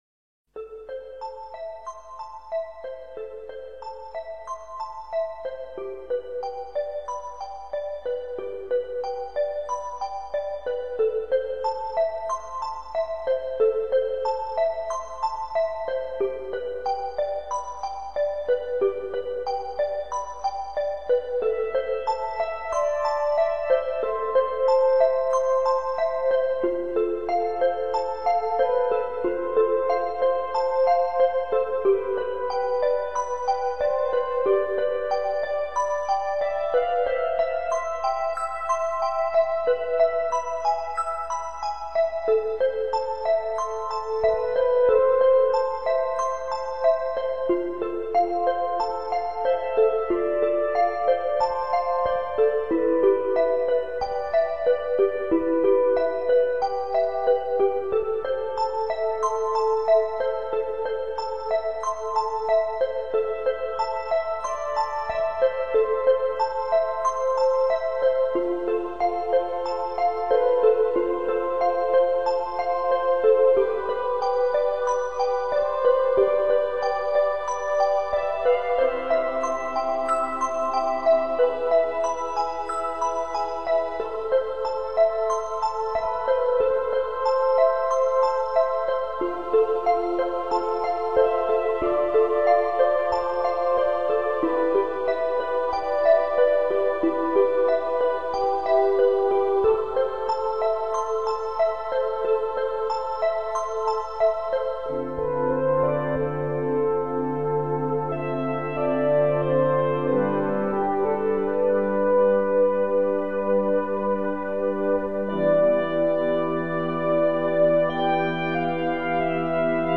落花 诵经 落花--佛教音乐 点我： 标签: 佛音 诵经 佛教音乐 返回列表 上一篇： 金玉观世音 下一篇： 青春幼苗 相关文章 无尽的思量Endless Thoughts--瑜伽静心曲 无尽的思量Endless Thoughts--瑜伽静心曲...